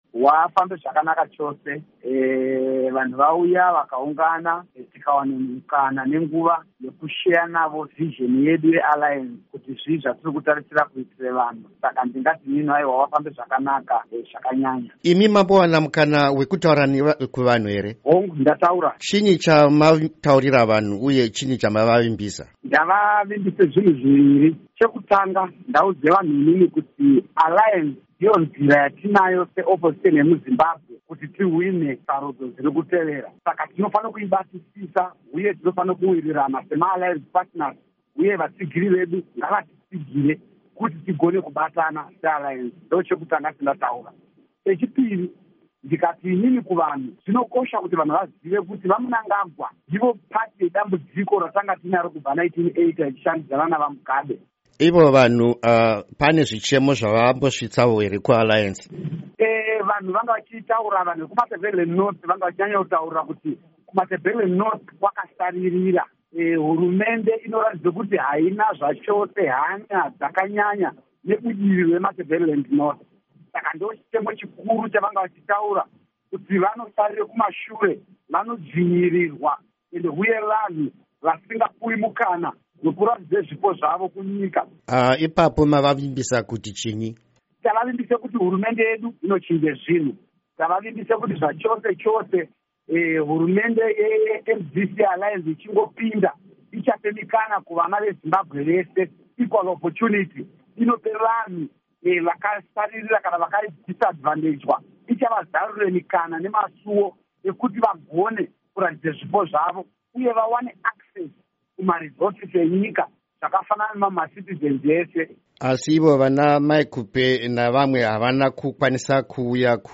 Hurukuro naVaJacob Ngarivhume